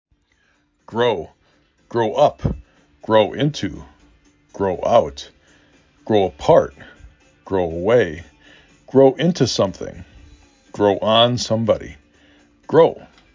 g r O
Local Voices
Cyprus